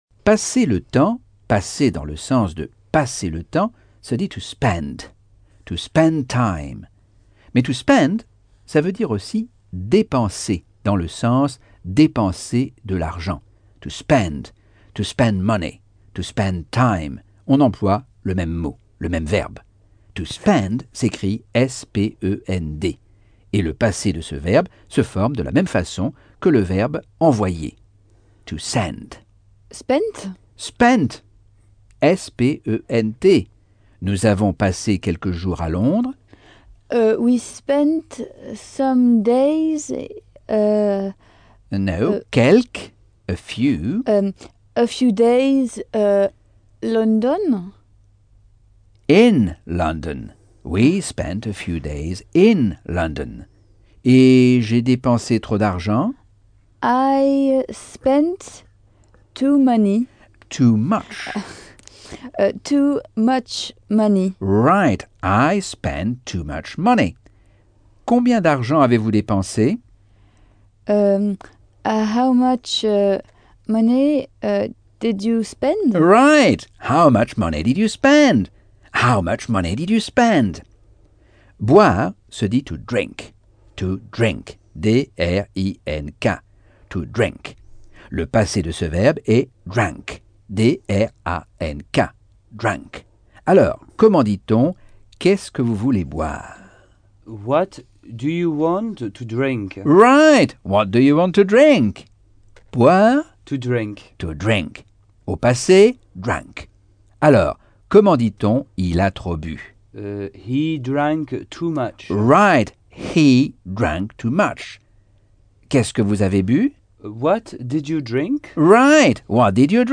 Leçon 5 - Cours audio Anglais par Michel Thomas - Chapitre 7